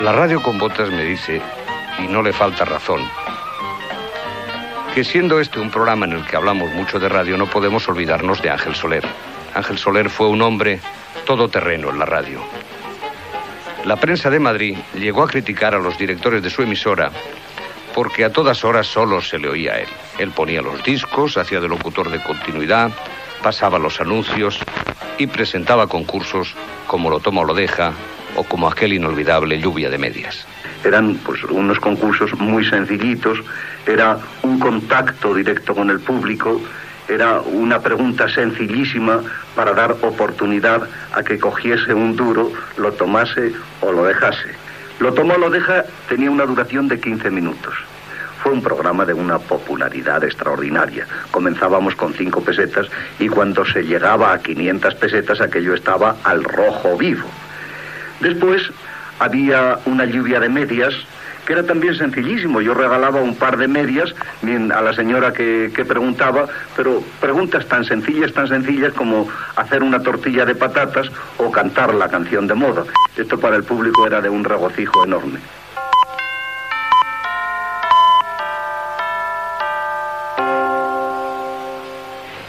Entrevista al locutor madrileny